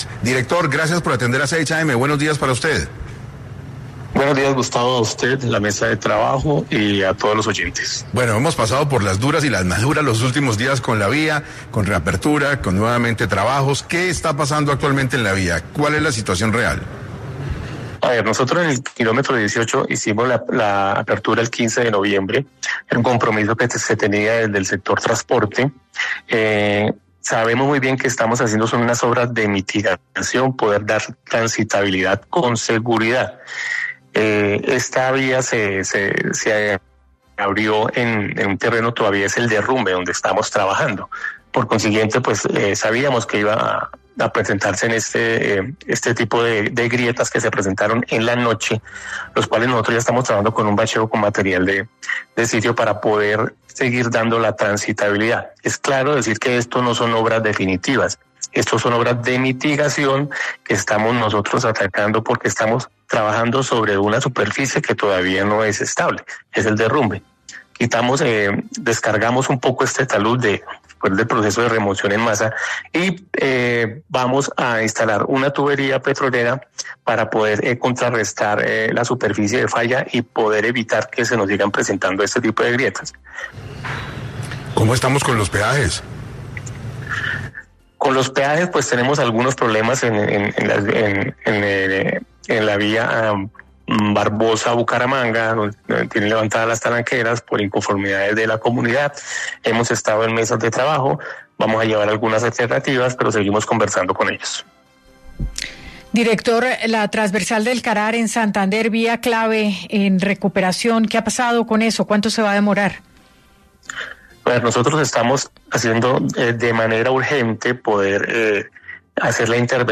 El director (E) del INVIAS, Instituto Nacional de Vías, habló en 6AM de cómo está la situación actual de la Vía Al Llano
En entrevista con 6AM de Caracol Radio, Jhon Jairo González, director (E) del INVIAS, Instituto Nacional de Vías indicó que se continúa con los trabajos en esta vía para poder habilitarla lo antes posible.